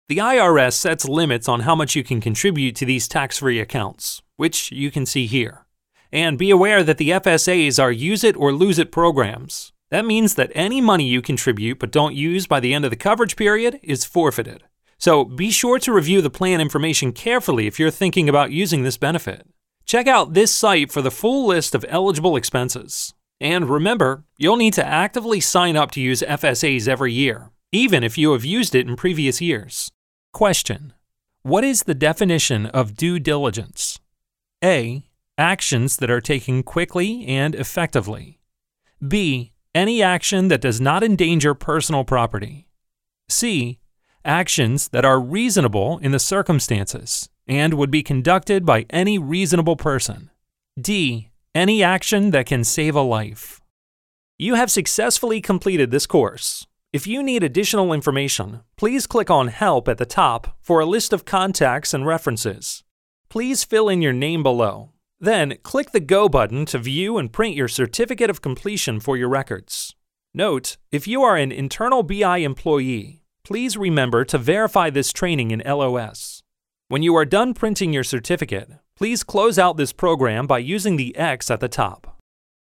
Male
English (North American)
Adult (30-50)
E-Learning
Professional, Educational
All our voice actors have professional broadcast quality recording studios.